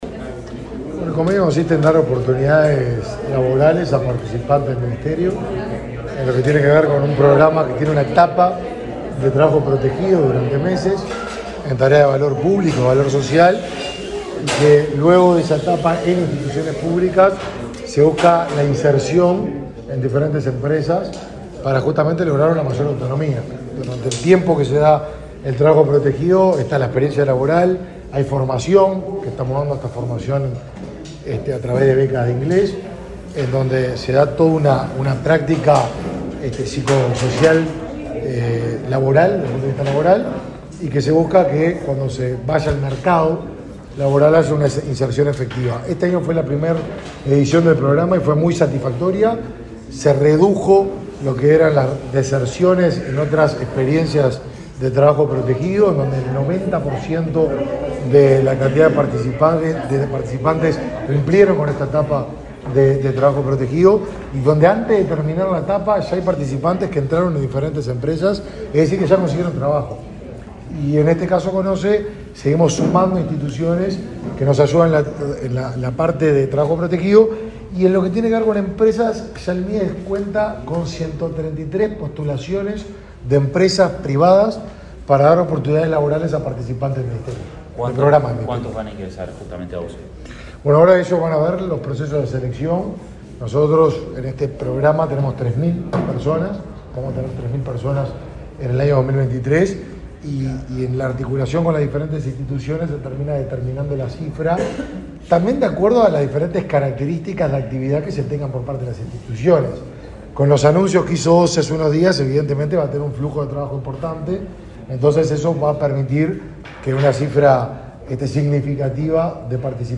Declaraciones del ministro de Desarrollo Social, Martín Lema
Declaraciones del ministro de Desarrollo Social, Martín Lema 30/12/2022 Compartir Facebook X Copiar enlace WhatsApp LinkedIn El ministro de Desarrollo Social, Martín Lema, efectuó declaraciones a la prensa, luego de firmar un convenio con autoridades de la OSE en el marco del programa socioeducativo y laboral Accesos.